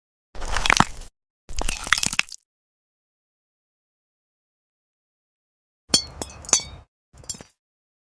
ASMR glass garden vegetables, Carrot